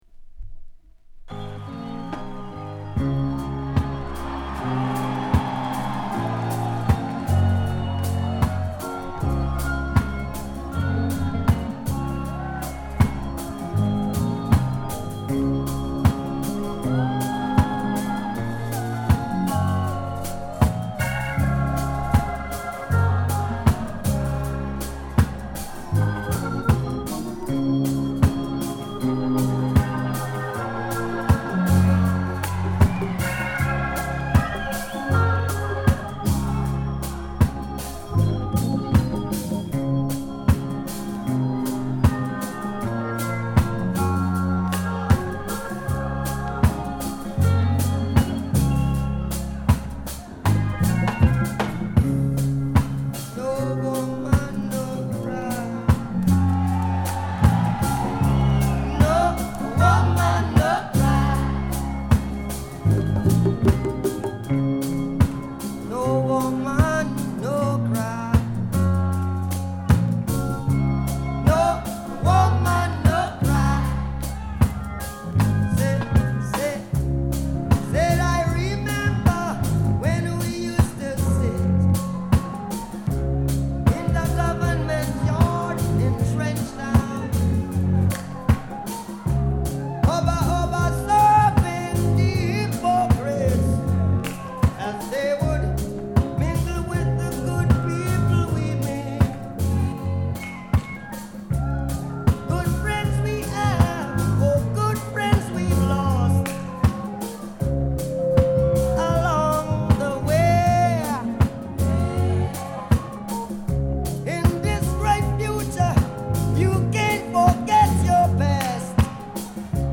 ポスター付き / Reissue / Remastered